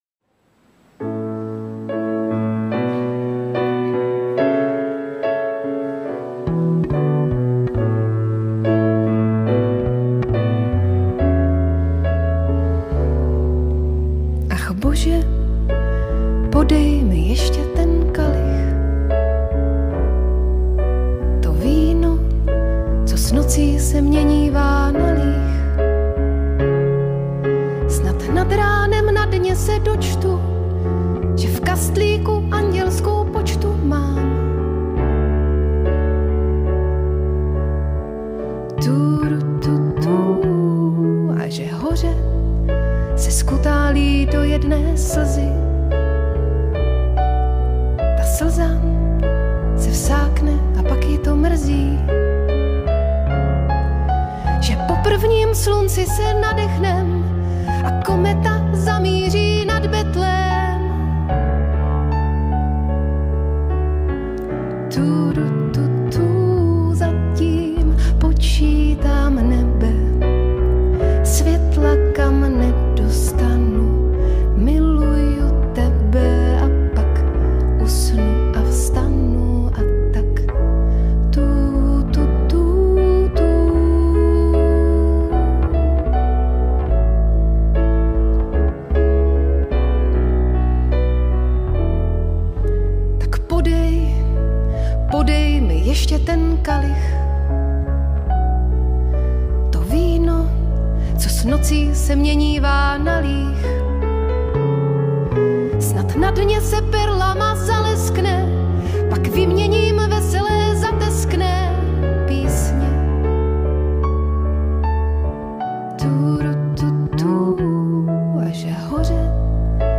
jen s klavírním doprovodem